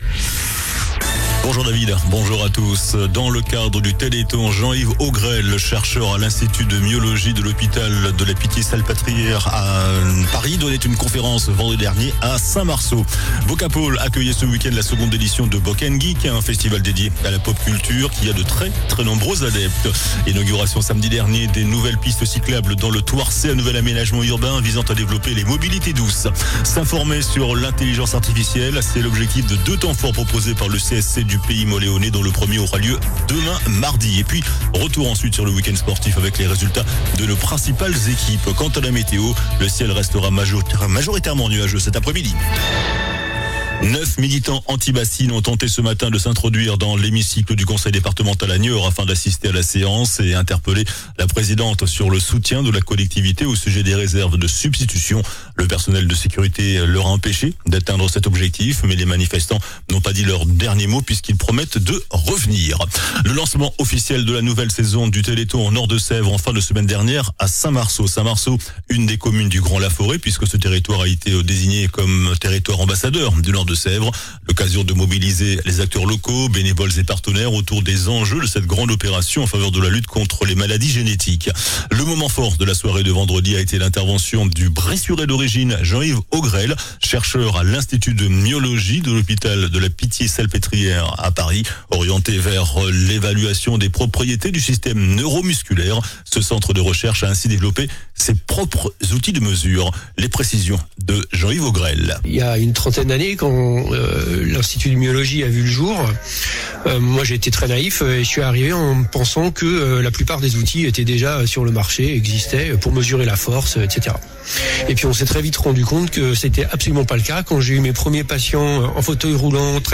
JOURNAL DU LUNDI 22 SEPTEMBRE ( MIDI )